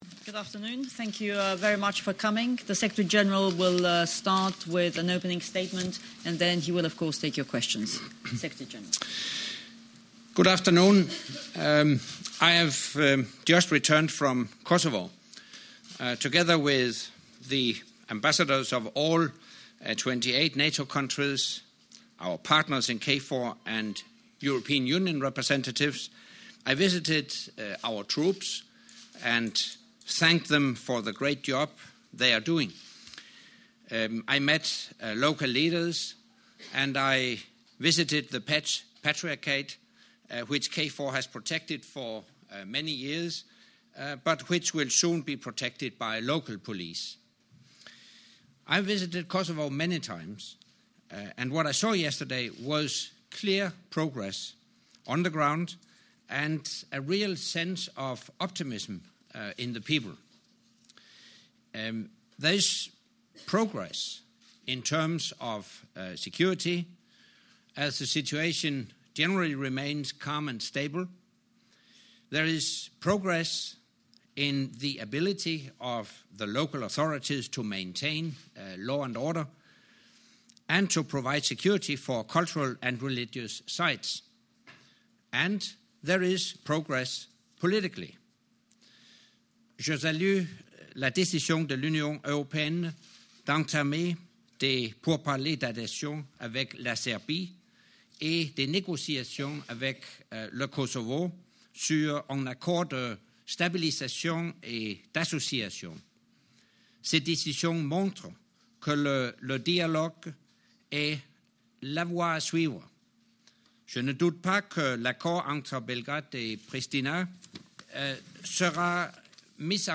Secretary General's Monthly Press Conference
NATO Secretary General Anders Fogh Rasmussen held his monthly press conference at the Residence Palace, Brussels.